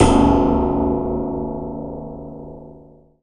SI2 PIANOS00.wav